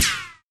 q3rally/baseq3r/sound/weapons/machinegun/ric2.ogg at e063f6a4fd5a9da3c6ef6abd101a91c961a2534e